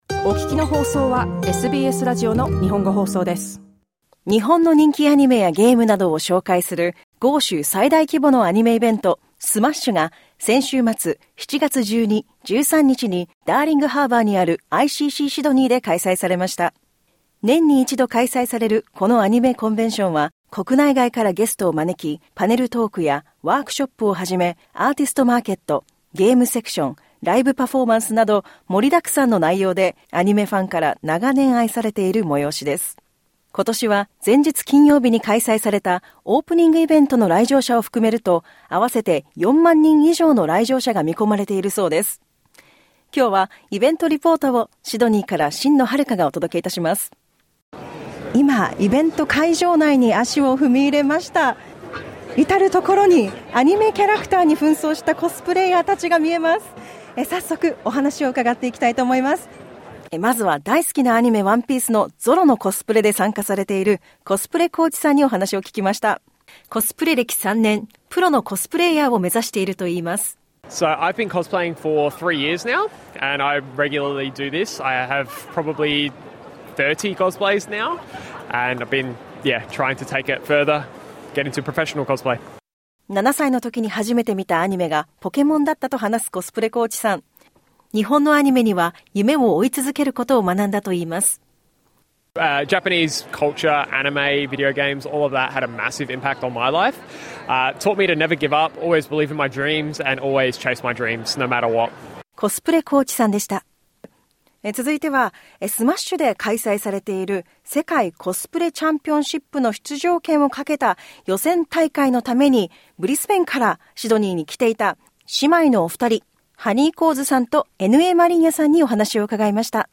詳しくは、SMASH!2025のイベント会場からのリポートをお聴きください。